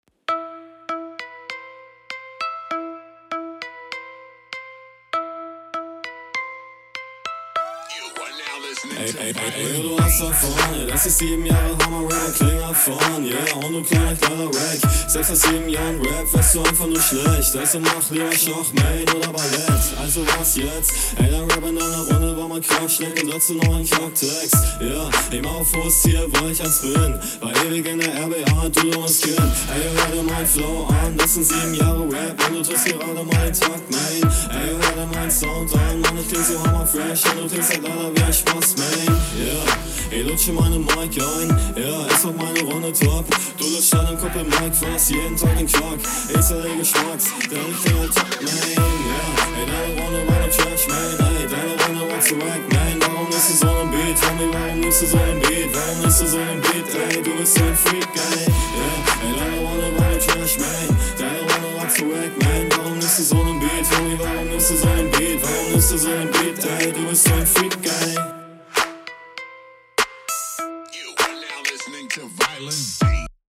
Stimme sehr nice.
Du nuschelst deinen Text irgendwie auf den Beat.